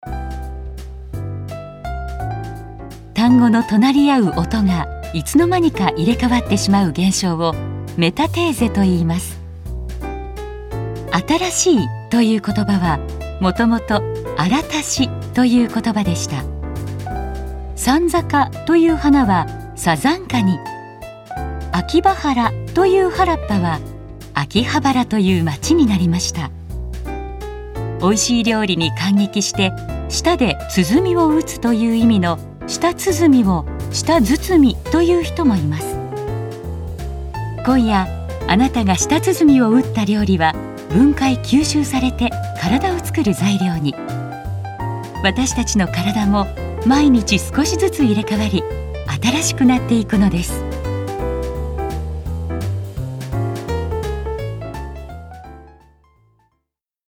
“美しさを感じる語り”は、彼女ならではのテイスト。